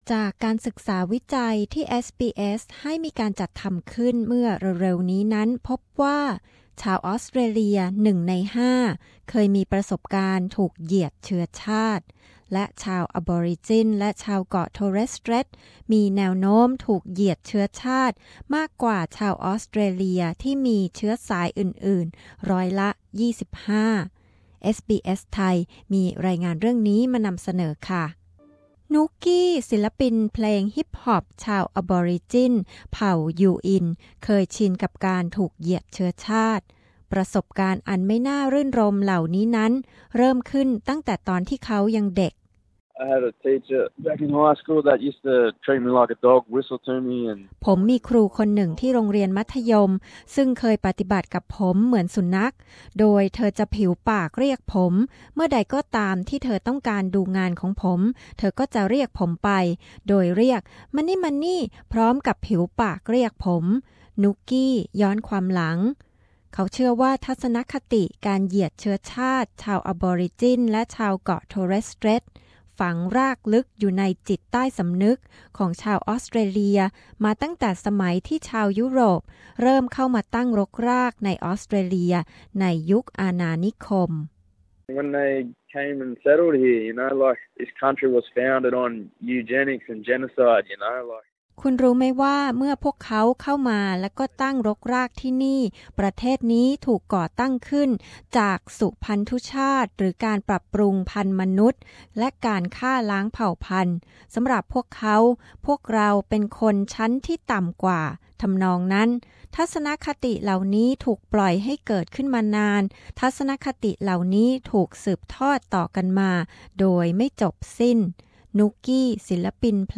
ชาวพื้นเมืองของออสเตรเลียมีแนวโน้มสูงกว่าคนอื่นที่จะถูกเหยียดเชื้อชาติ เราร่วมกันจะแก้ปัญหานี้ได้อย่างไร เอสบีเอส มีรายงานพิเศษ